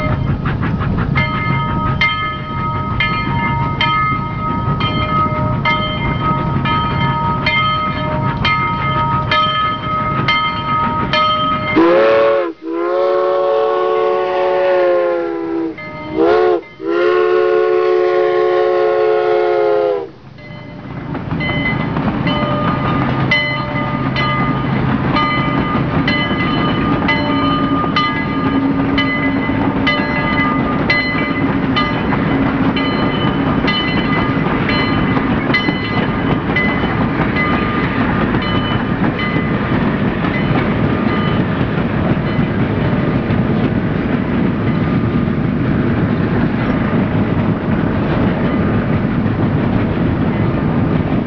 Train
Train.wav